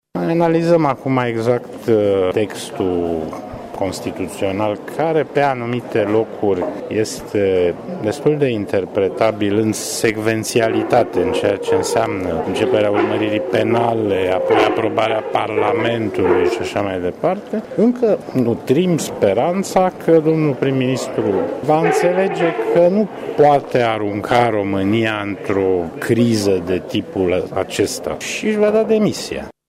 Dan Mihalache a declarat la Tîrgu-Mureș că în acest moment se analizează la nivelul Administrației Prezindețiale textele constituționale care reglementează posibilitățile de acțiune ale Președintelui în condițiile în care Primul Ministru este urmărit penal.
Dan Mihalache a fost prezent la Tîrgu –Mureș pentru a vorbi la Salonul de carte Bookfest despre ultimul volum al președintelui Klaus Iohannis ”Primul Pas” și despre studiul programelor electorale ale candidaților la prezindețialele din 2014 ”România între lucrul bine făcut și marea unire” scrisă de Daniel Buțiș Alexandru Radu.